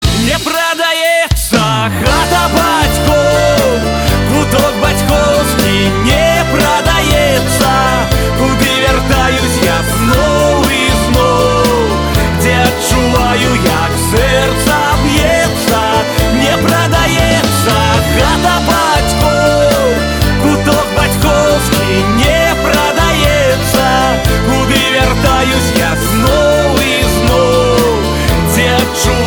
душевные